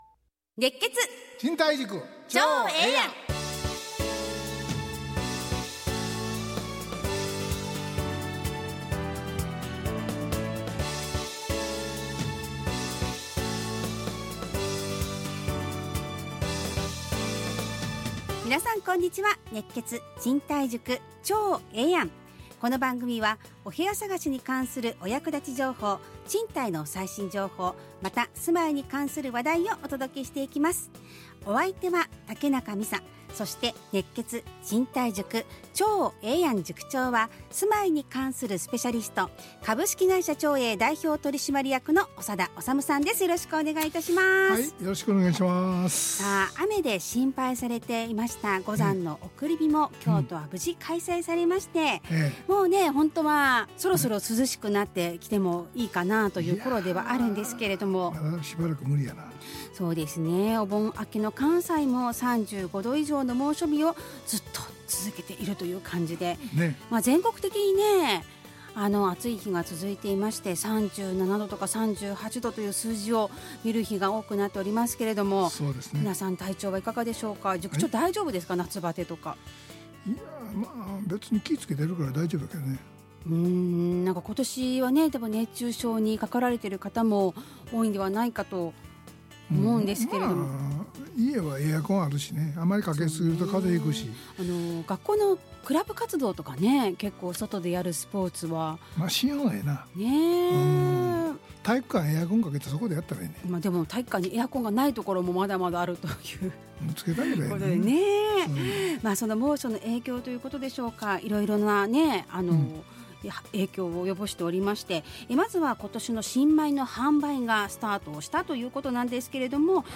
ラジオ放送 2025-08-25 熱血！